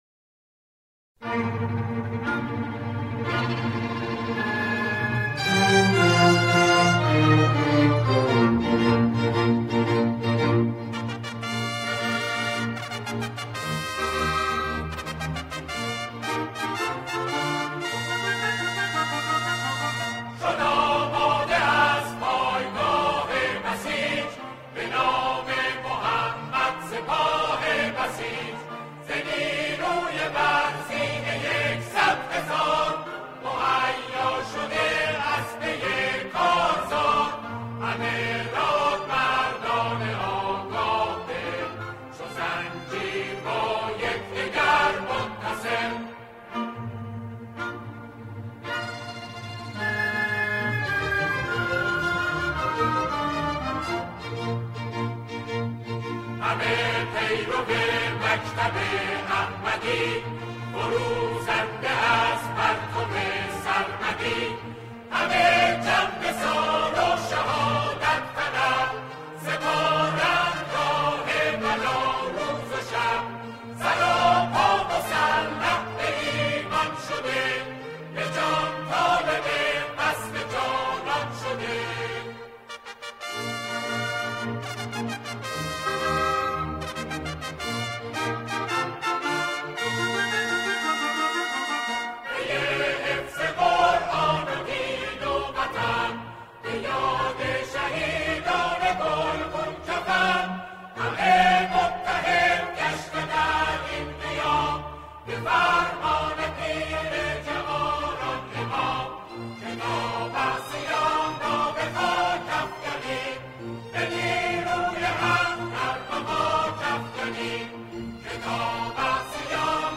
سرودهای بسیج